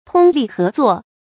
注音：ㄊㄨㄙ ㄌㄧˋ ㄏㄜˊ ㄗㄨㄛˋ
通力合作的讀法